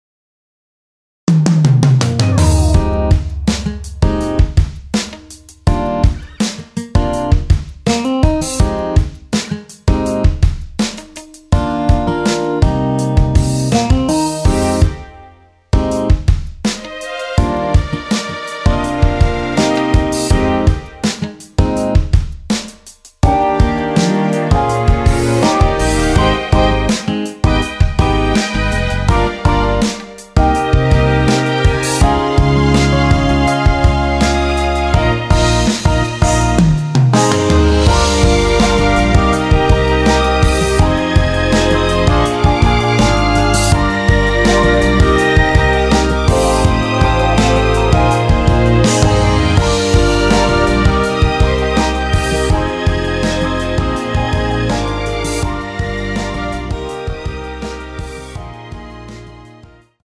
원키에서(+4)올린 MR입니다.
Eb
앞부분30초, 뒷부분30초씩 편집해서 올려 드리고 있습니다.